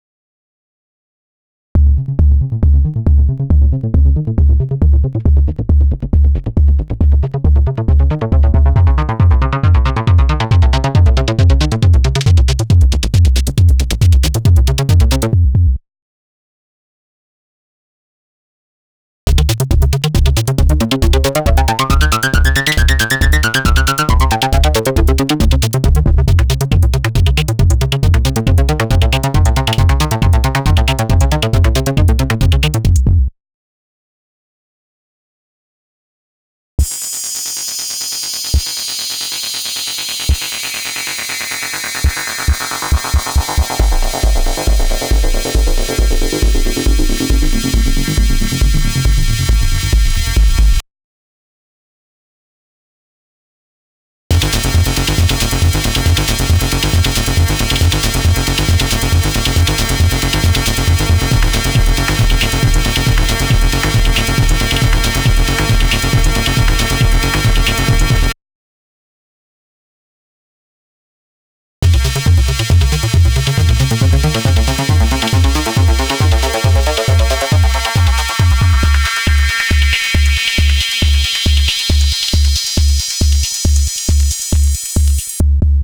demo 3: edm fundamentals
input: ade-20 filter kick, white noise hi-hats, sawtooth lead.
cv: various lfo and gate/trigger patterns.